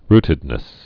(rtĭd-nĭs, rtĭd-)